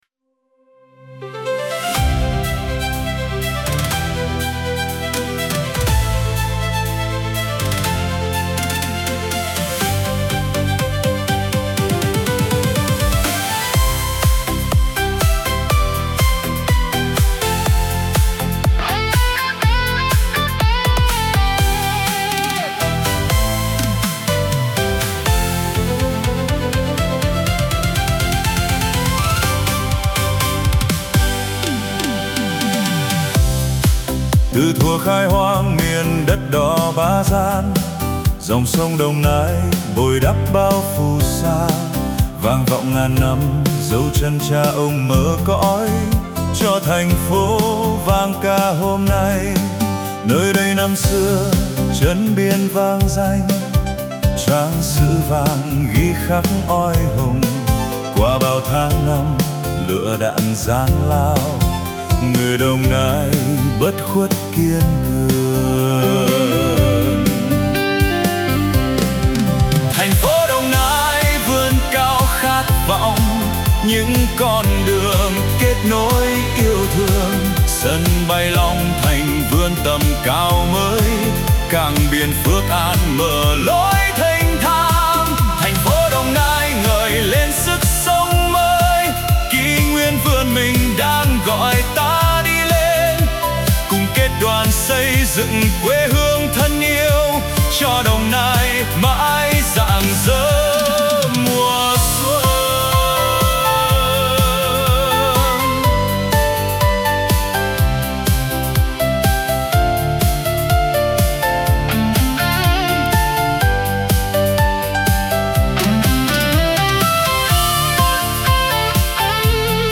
giai điệu tươi sáng, giàu cảm xúc